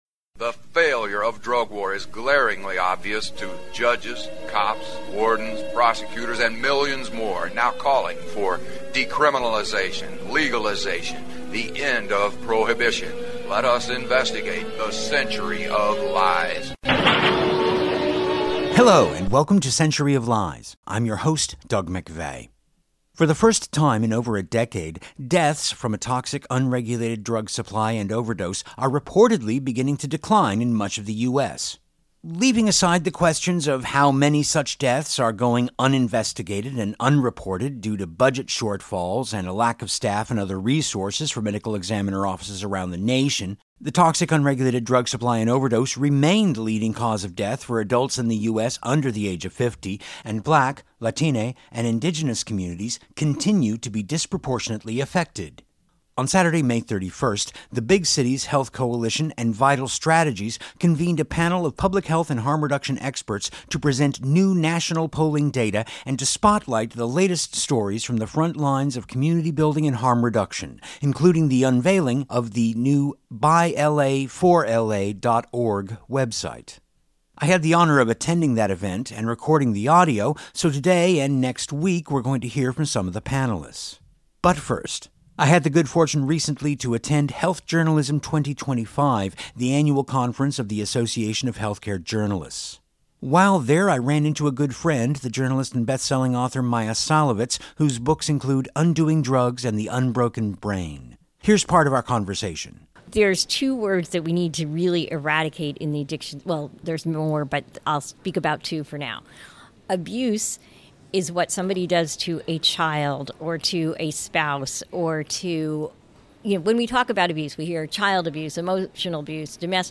Plus, we hear part of an interview with the journalist and best-selling author Maia Szalavitz. play pause mute unmute KBOO